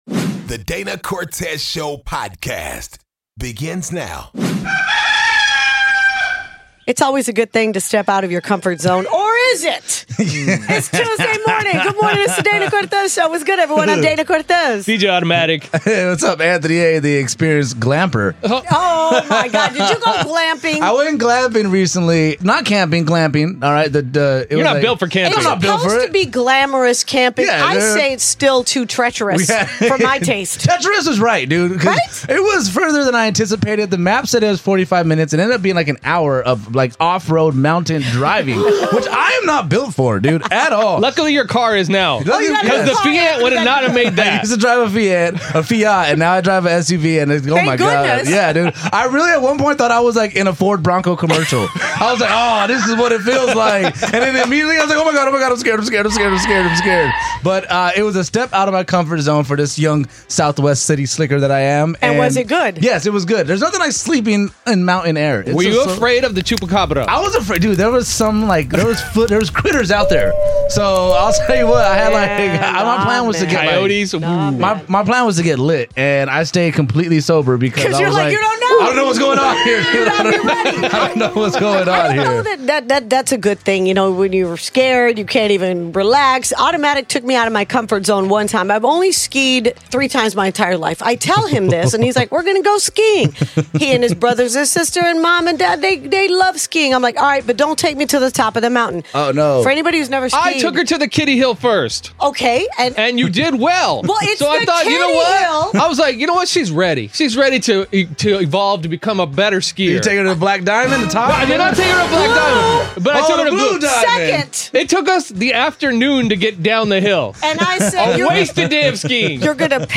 A Listener Was Almost Kidnapped! She Tells The Story to Dana!